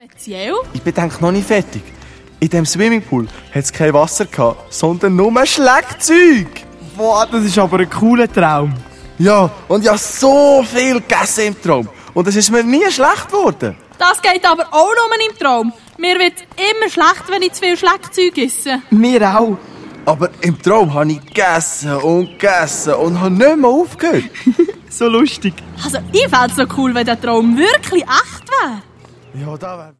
Zusätzlich enthalten: Mehrere Songs, das Hörspiel «Iifersucht» der lustigen Bärenkinder der Adonia-KidsParty
Hörspiel-Album